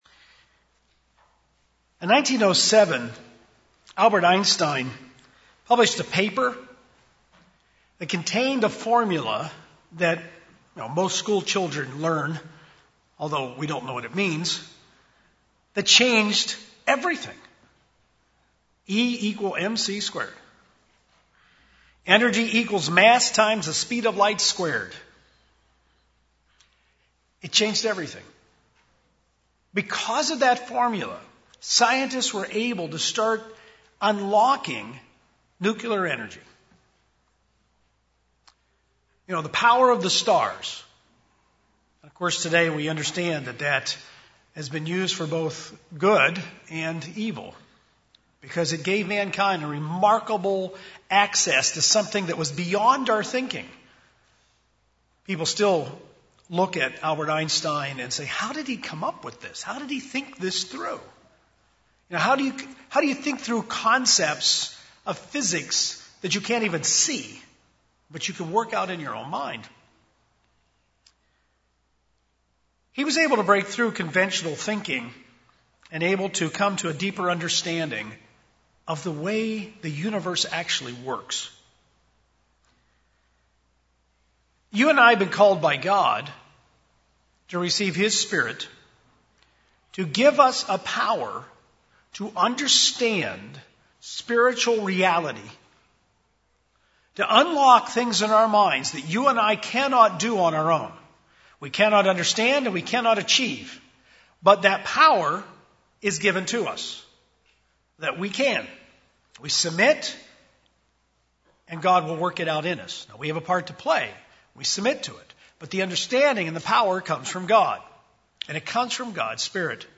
The Greek word used for love in this passage is "agape", it describes the very character of God. Practical applications of how to grow in this fruit are outlined in this sermon.